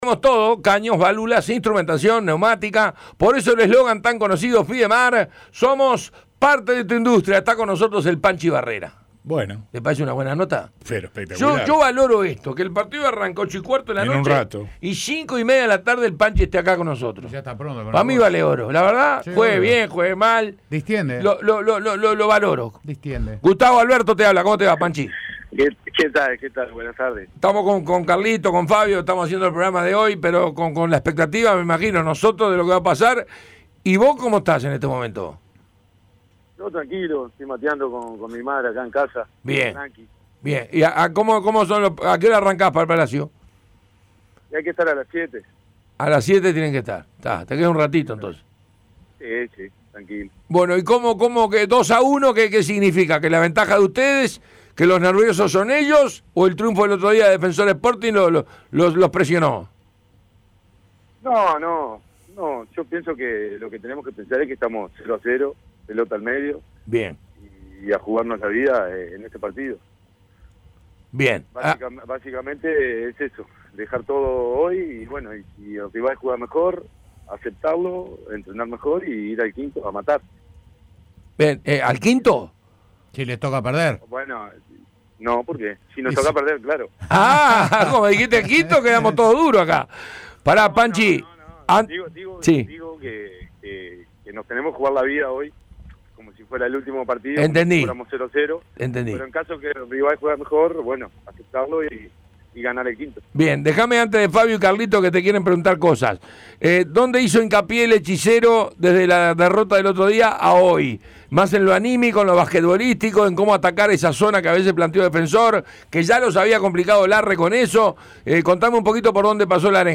Entrevista completa.